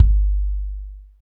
KIK H H RA00.wav